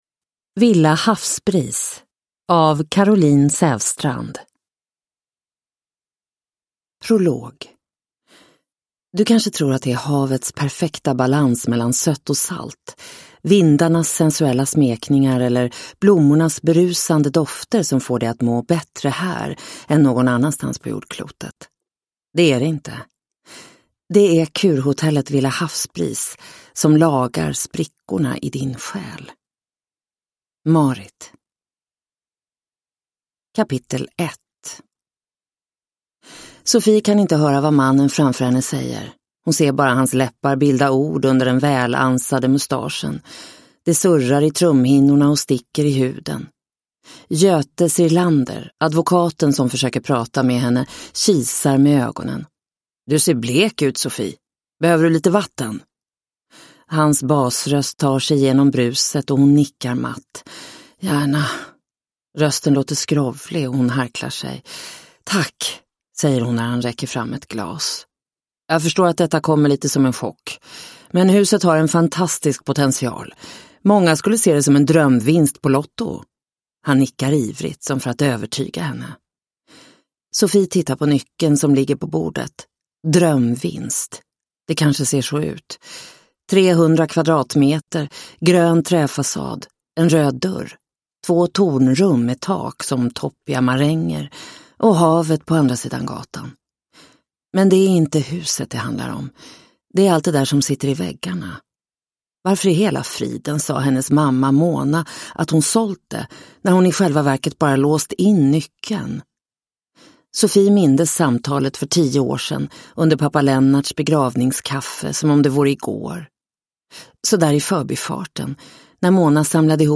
Villa Havsbris – Ljudbok